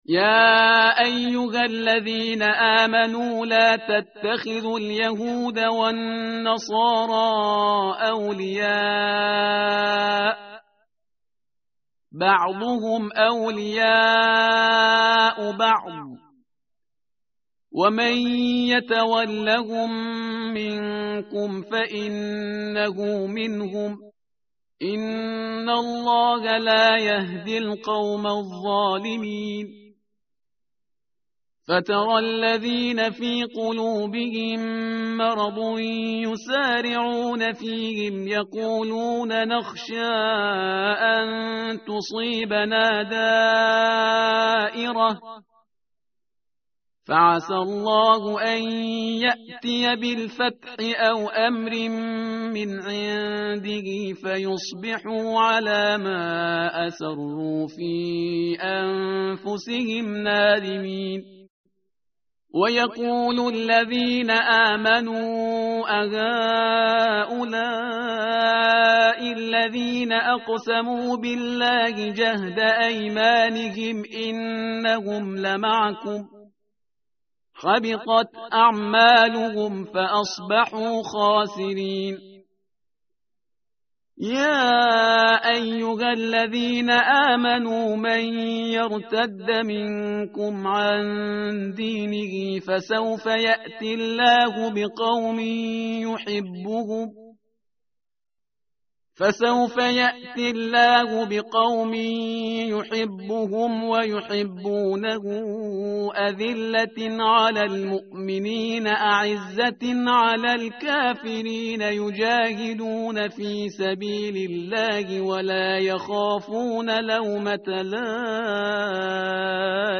متن قرآن همراه باتلاوت قرآن و ترجمه
tartil_parhizgar_page_117.mp3